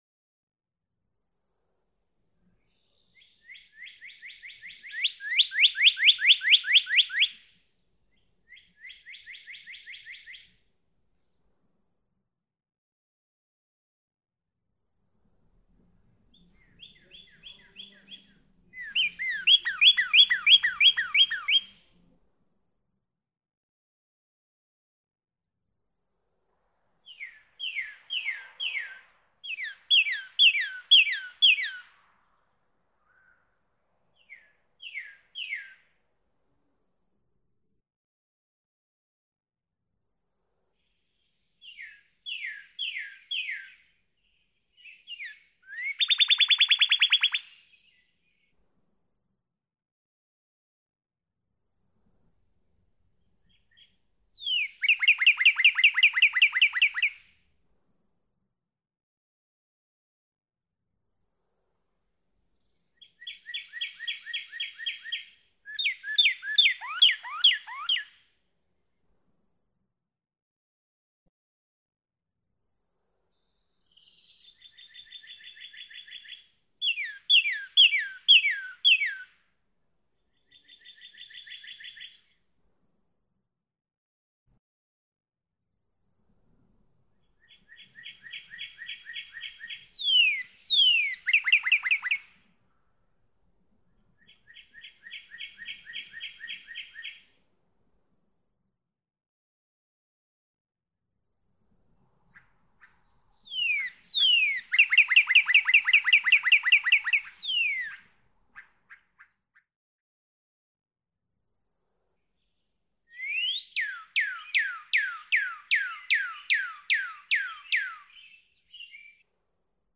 ♫60. Ten examples of the female singing from the nest, with examples separated by fades. Now a monaural microphone is used (with a Swift recorder—on the home page for this website, click on LEARN MORE, then RECORDING BIRDSONG), so only relative loudness of the songs can identify the individual; again, the loud, near bird is the female. In examples 1 through 4, the female matches the male's song; examples 5 through 8, no matching; examples 9 and 10, isolated female songs with no male audible. May 2018. Hatfield, Massachusetts. (2:01)
Example Beginning time in recording Content (female loud and near, male more distant)
4 0:39 1 male song, 1 female song. Listen carefully: at 0:45 the female matches the male with a single note, but then she switches to another song type.
060_Northern_Cardinal.mp3